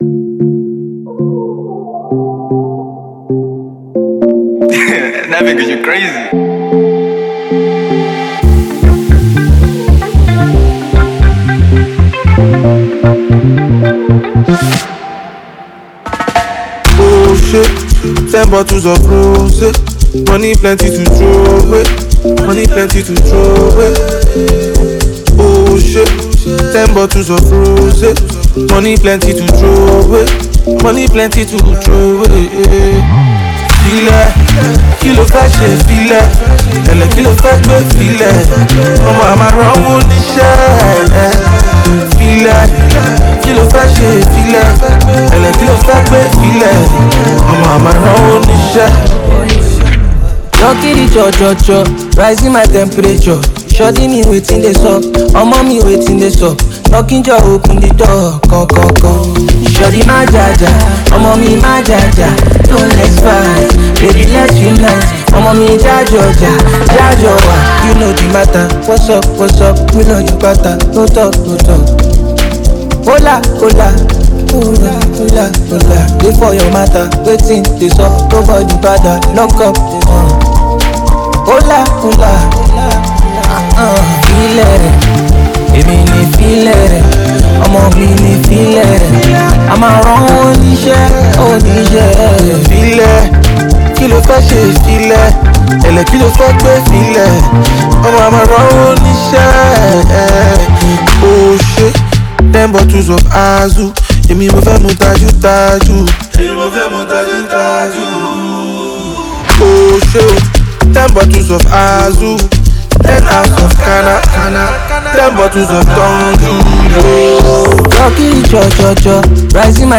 Talented Nigerian hip-hop sensation